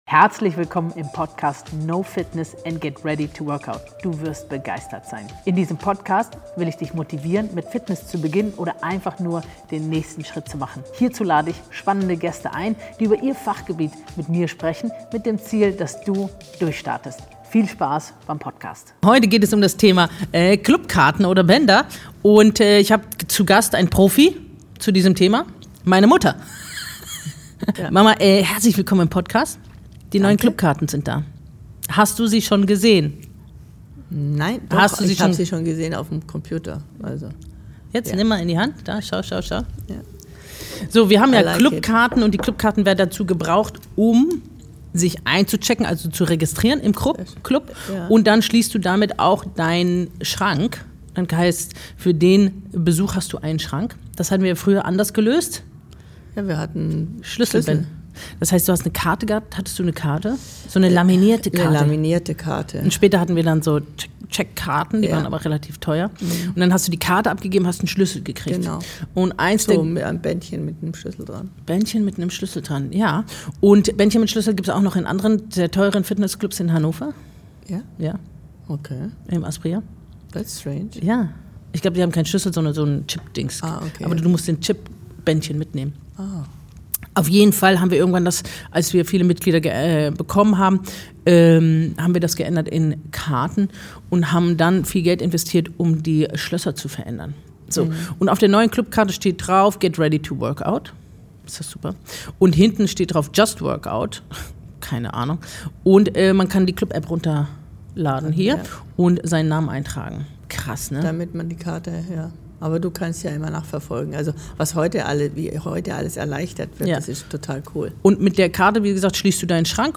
Die beiden plaudern über Studiostrukturen, Mitgliederverhalten, Kurskultur und den Wandel in der Fitnessbranche – von Gummiband-Workouts bis zur Pink Area. Eine Folge voller Insiderwissen, Anekdoten und dem Beweis, dass selbst Technikfragen im Fitnessstudio für ordentlich Gesprächsstoff sorgen.